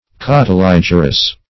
Search Result for " cotyligerous" : The Collaborative International Dictionary of English v.0.48: Cotyligerous \Cot`y*lig"er*ous\ (k?t`?-l?j"?r-?s), a. [Cotyle + -gerous.]
cotyligerous.mp3